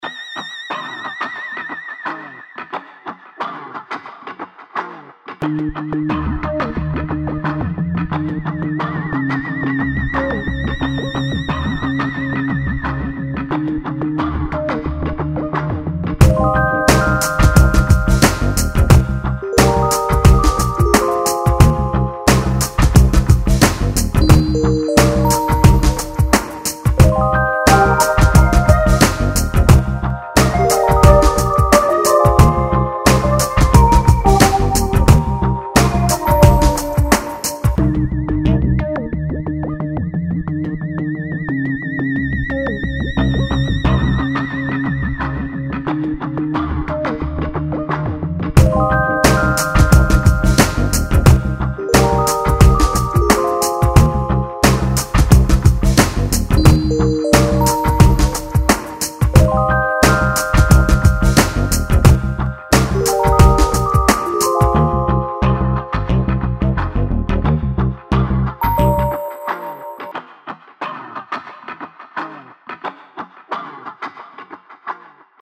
lounge - chill - doux - synthetiseur - aerien